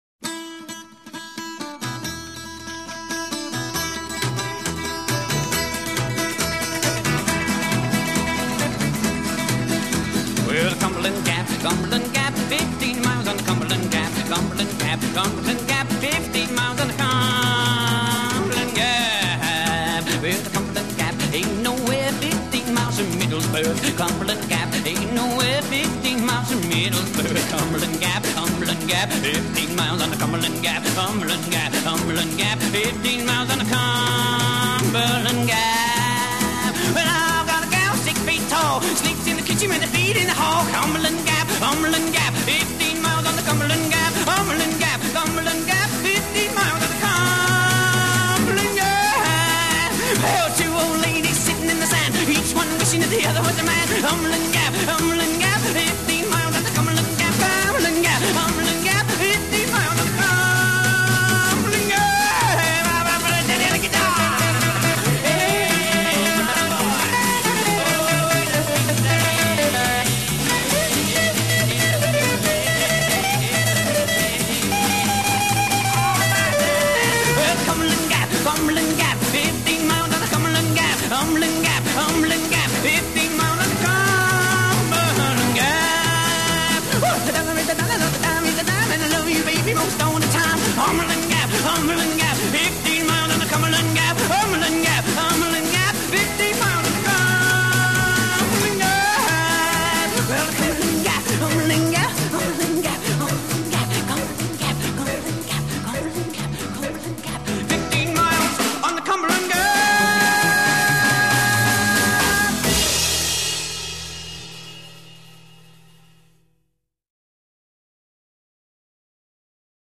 Recorded 24 February 1957 at Pye Studios, London.
B Verse   16 electric guitar solo -
Skiffle